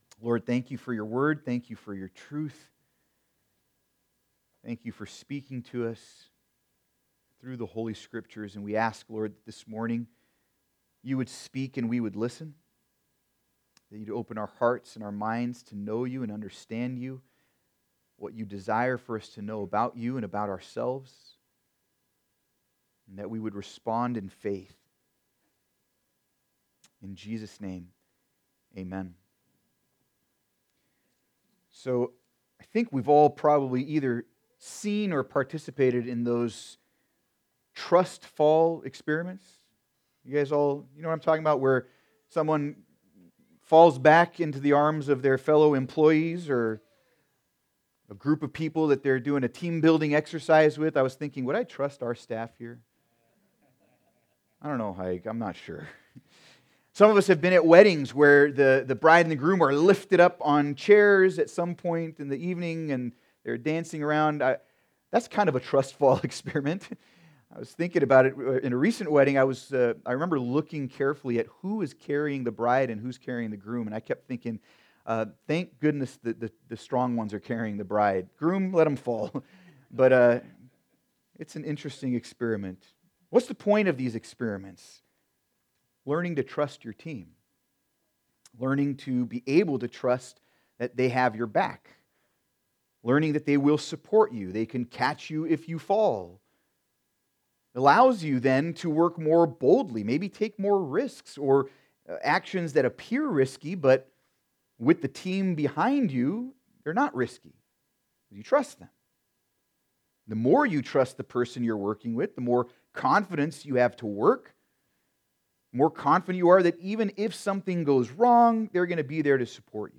Sermon
Service Type: Sunday Service